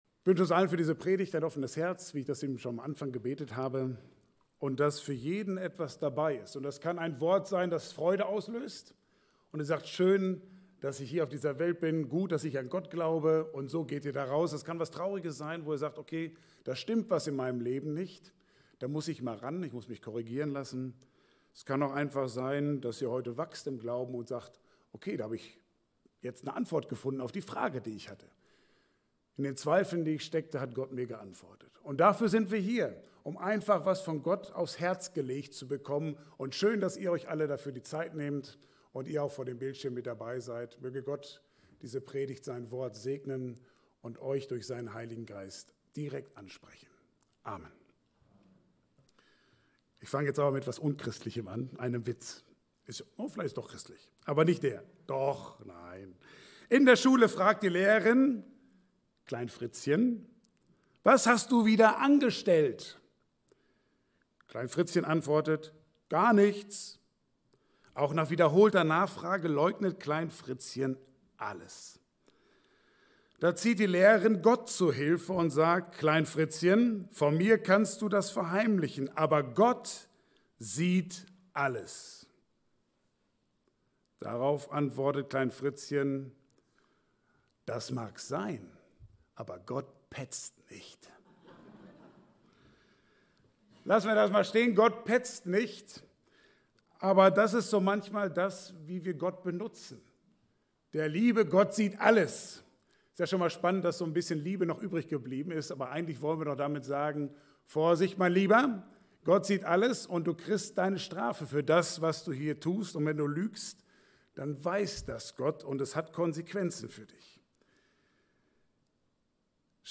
Bibelstelle: Jesaja 54, 7-10 Dienstart: Gottesdienst « Judas